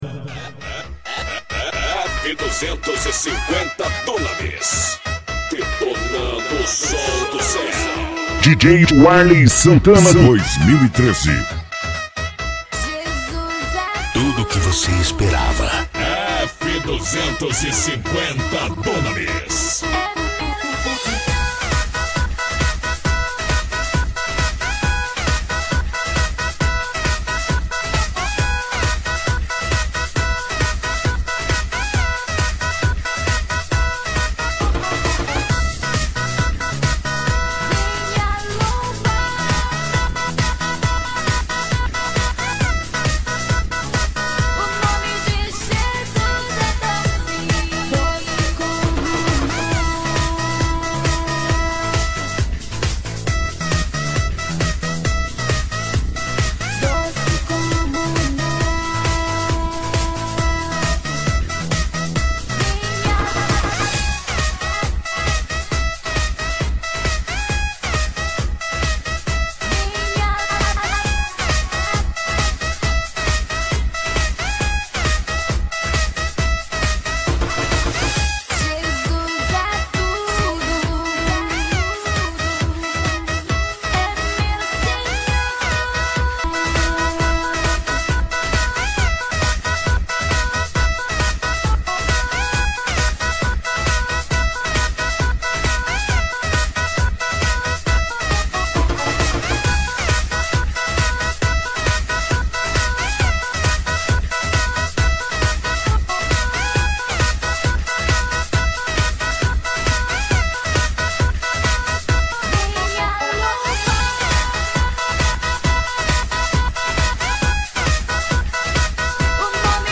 Composição: remix.